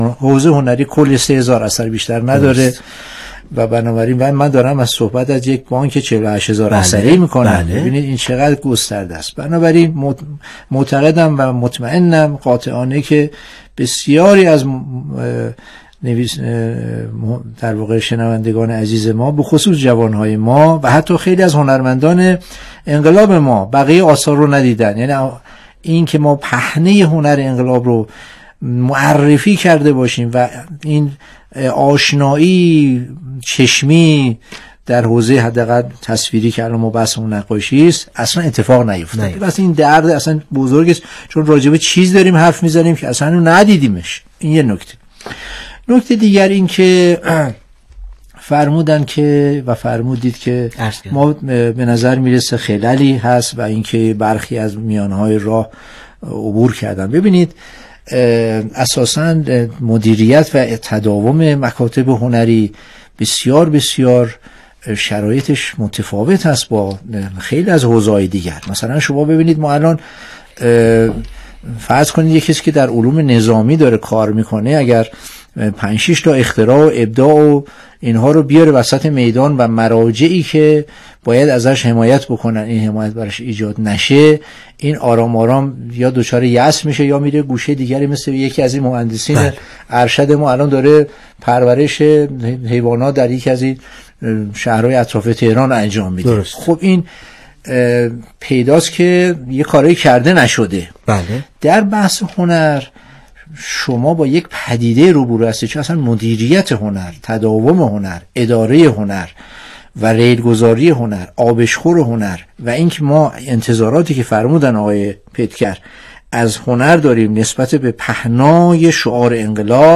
میزگردی تعاملی